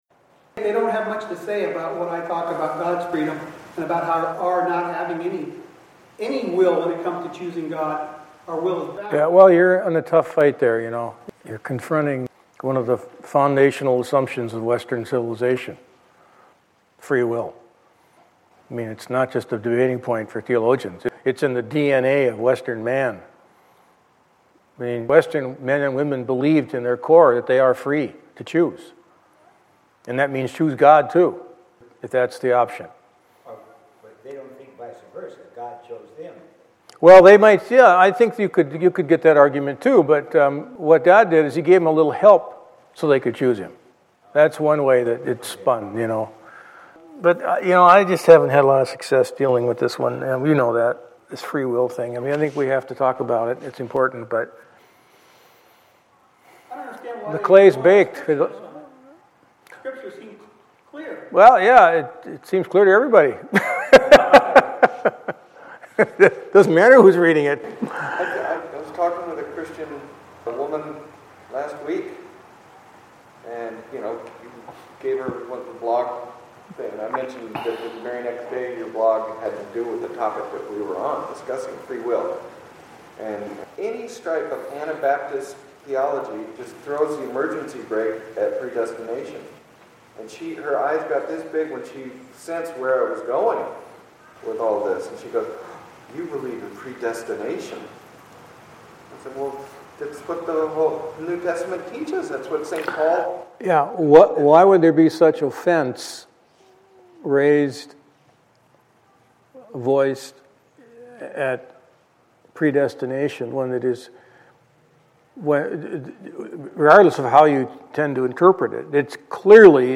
Discussion of “free-will” and predestination
Pastor's class
predestination-and-free-will-discussion.mp3